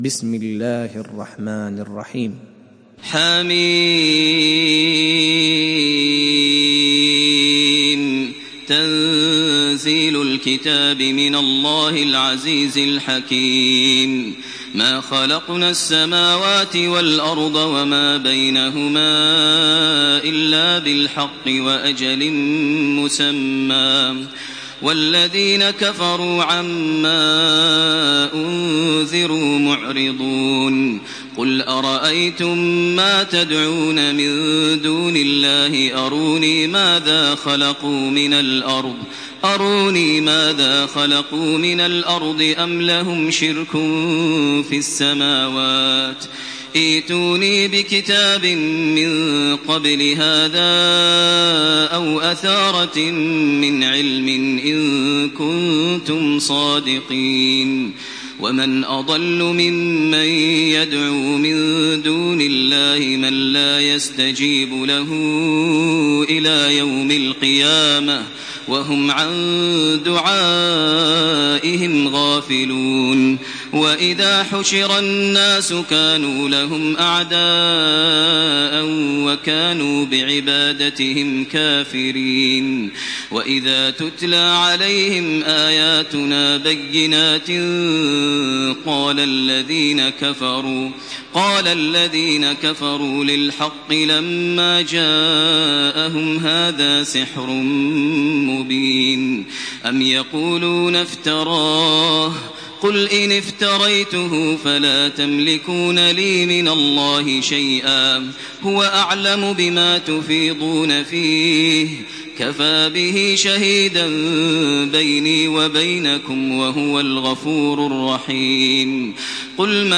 سورة الأحقاف MP3 بصوت تراويح الحرم المكي 1428 برواية حفص
مرتل